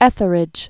(ĕthər-ĭj, ĕthrĭj), Sir George 1635?-1692?